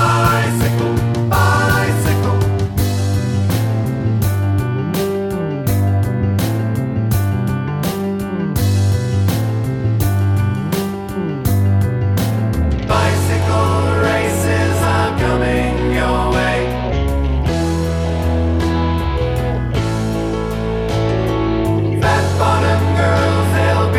Minus Guitars Rock 3:01 Buy £1.50